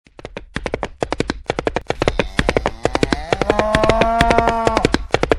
B_SABOTS_01.mp3